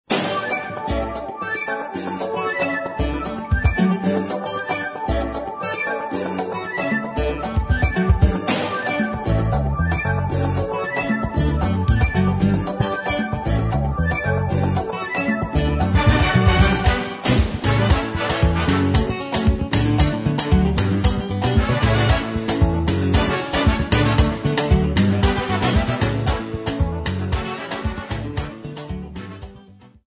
lead singer, bolon, kora
chorus
percussions
guitar solo
congas
rythm guitar, solo
trumpet
trombone
saxophone
keyboard
Popular music--Africa, West
Cassette tape